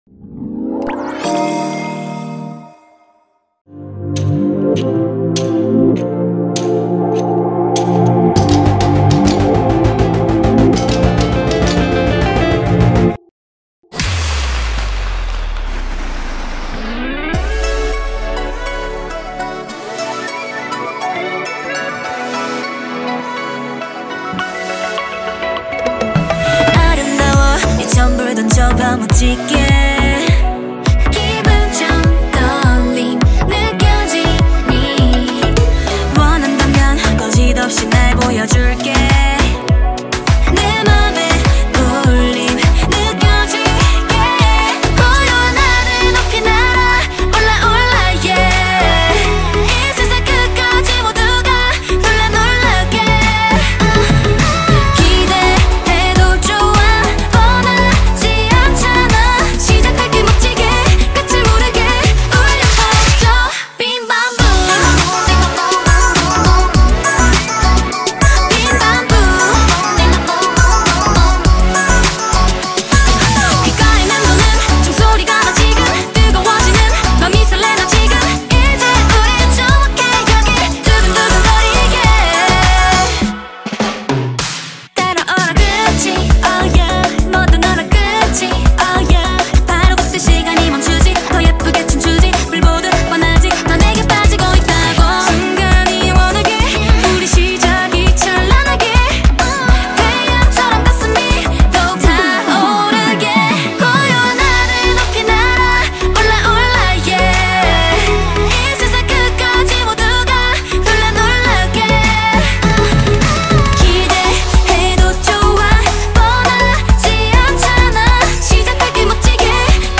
Grup vokal wanita
Kpop Mp3